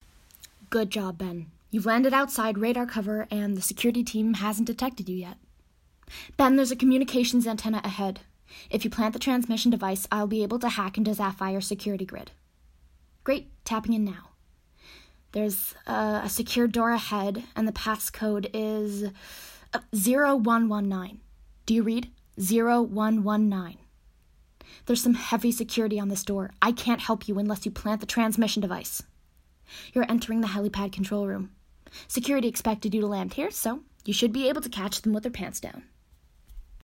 Video games - EN